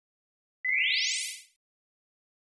دانلود صدای تلپورت 1 از ساعد نیوز با لینک مستقیم و کیفیت بالا
جلوه های صوتی
برچسب: دانلود آهنگ های افکت صوتی طبیعت و محیط دانلود آلبوم صدای تلپورت (غیب شدن) از افکت صوتی طبیعت و محیط